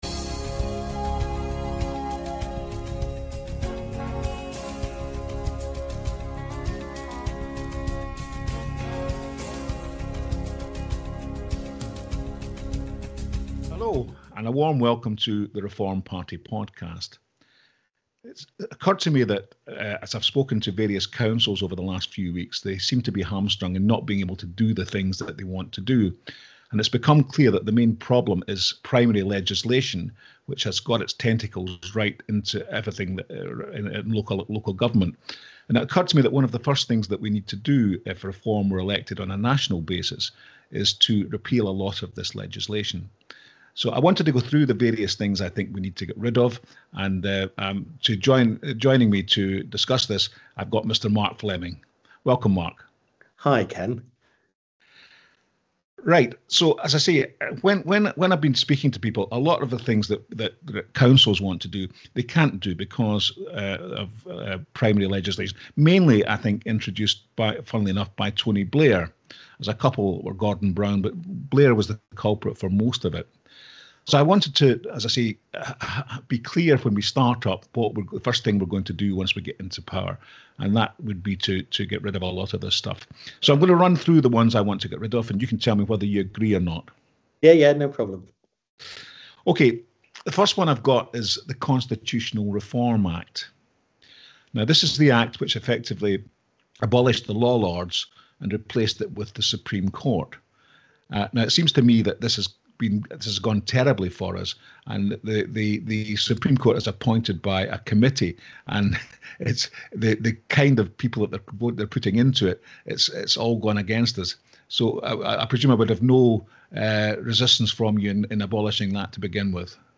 🔑 Don’t miss this fascinating conversation packed with hard-hitting truths and actionable solutions.